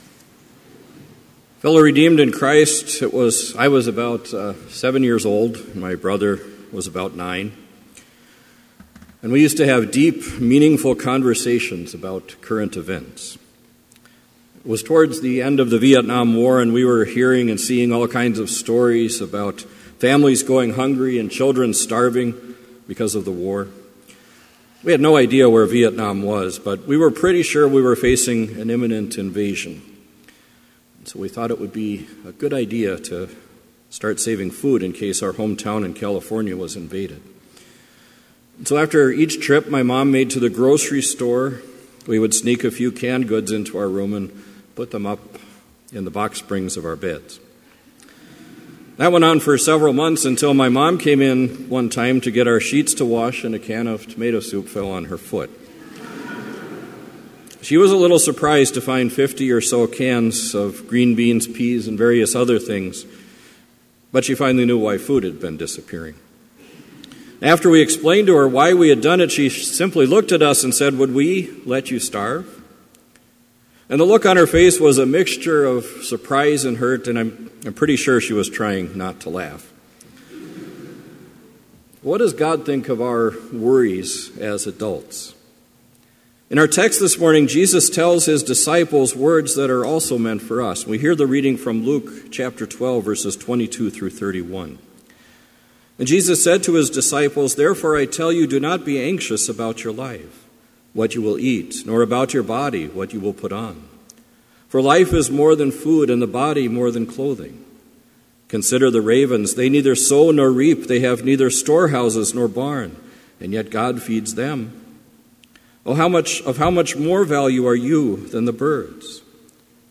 Complete Service
• Prelude
• Hymn 205, vv. 1-4, If Thou But Trust in God to Guide Thee
• Devotion
This Chapel Service was held in Trinity Chapel at Bethany Lutheran College on Wednesday, February 1, 2017, at 10 a.m. Page and hymn numbers are from the Evangelical Lutheran Hymnary.